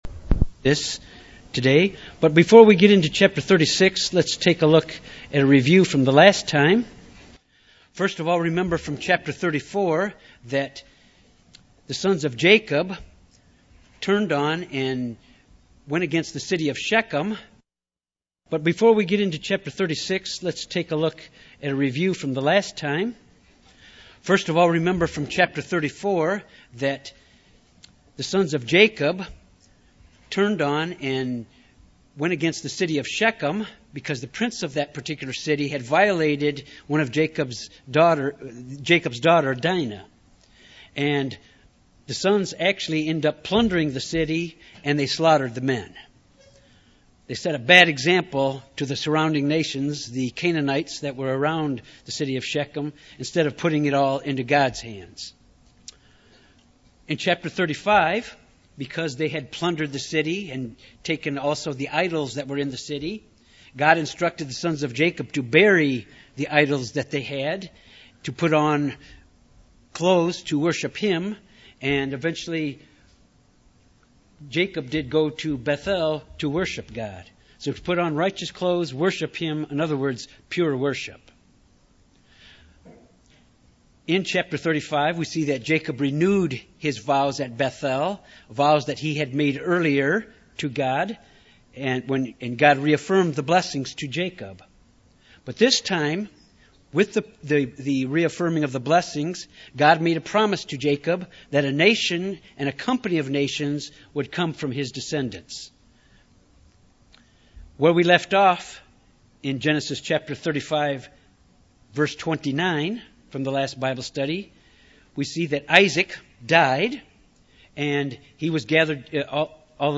Bible study: Chapter 36 is a history of Esau and his descendants that become a powerful nation.